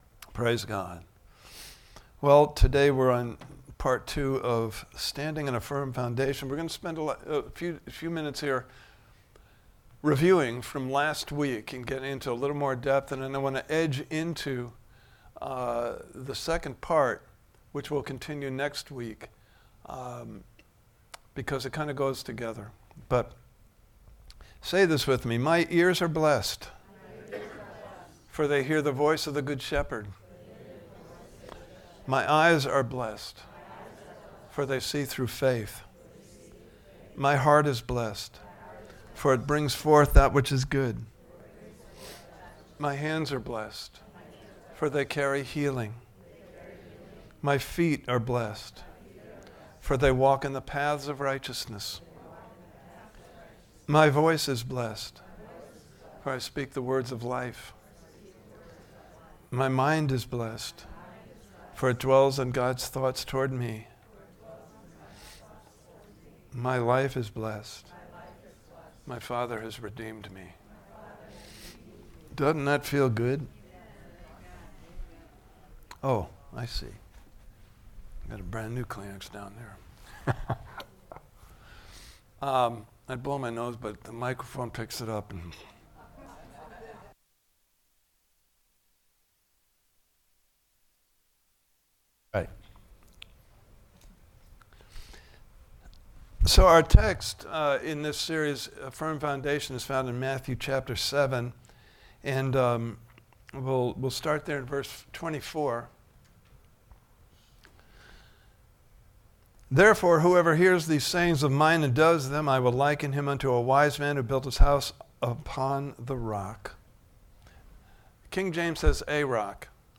Standing on a Firm Foundation Service Type: Sunday Morning Service « Part 1